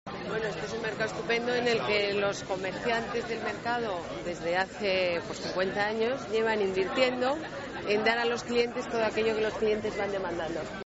Nueva ventana:Declaraciones alcaldesa, Ana Botella, visita plaza de Prosperidad y mercado Chamartín